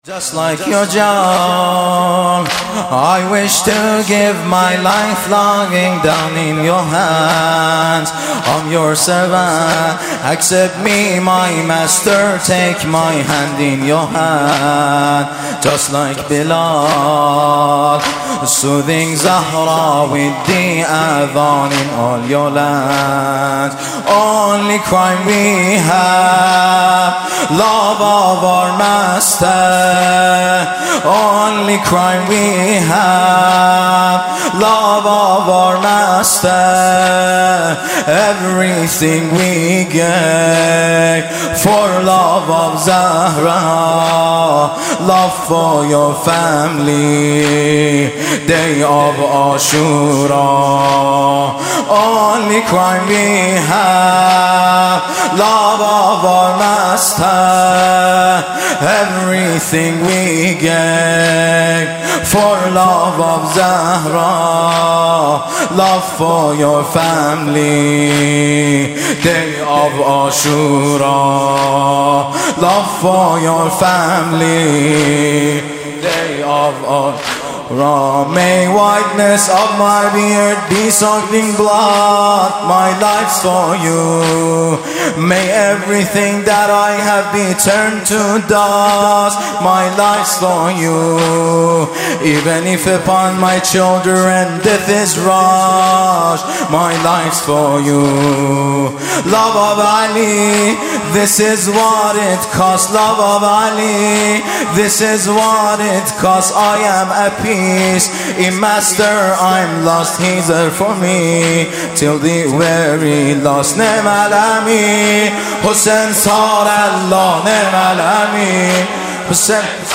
مداحی انگلیسی یادبودمسلمانان نیجریه+صوت
این مداحی انگلیسی به مناسبت اربعین شهدای مسلمان نیجریه و به یاد شیخ زکزاکی در هیئت شهدای گمنام اجرا شد
عقیق:فایل صوتی مداحی انگلیسی که در تاریخ اول بهمن 94 به یاد مسلمانان مظلوم نیجریه در هیئت شهدای گمنام اجرا شده، آماده دریافت است.